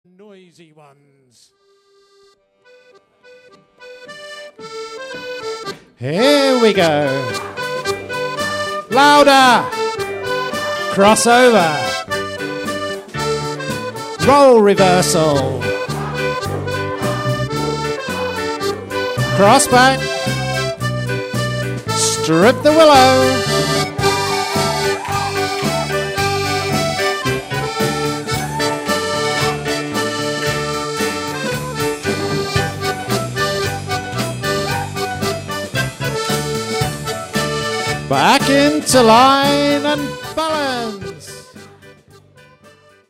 If you have a fast link, or are very patient, you can hear some short MP3 samples of the band from recent ceilidhs by clicking the dancing logos below.